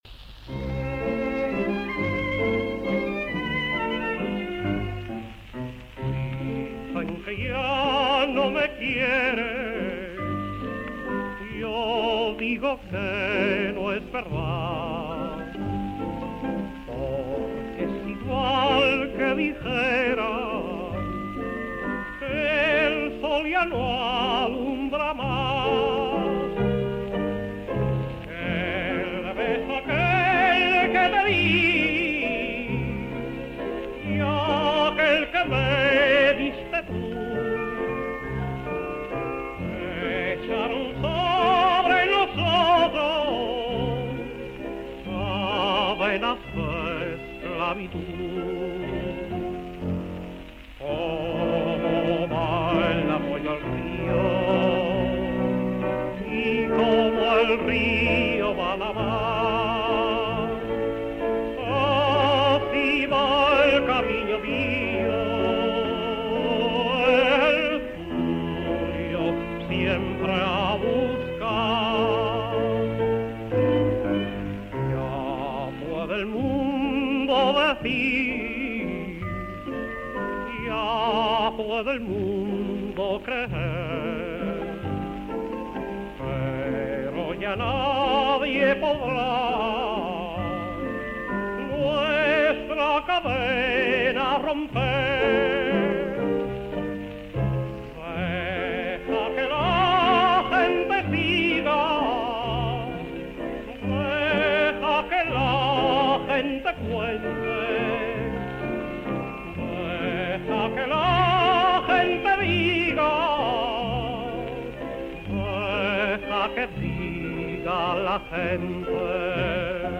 Tino Folgar sings